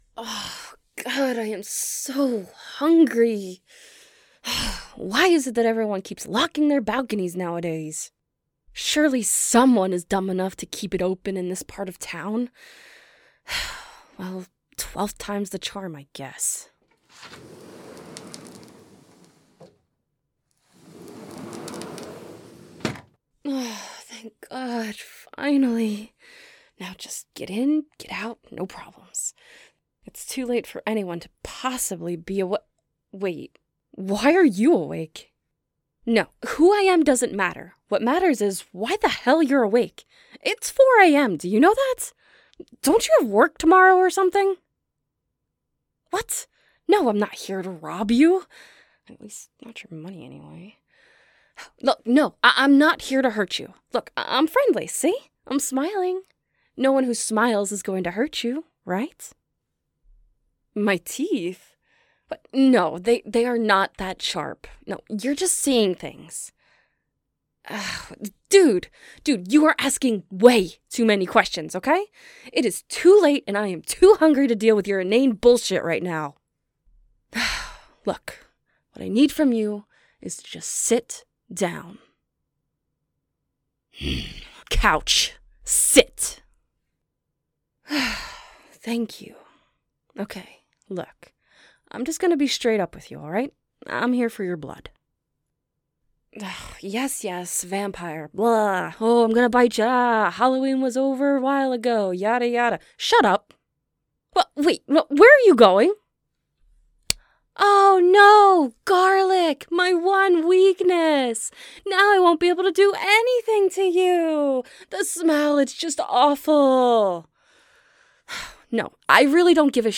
[Hungry Vampire] [Bringing You Food] [Biting/Feeding] [Hypnotizing You]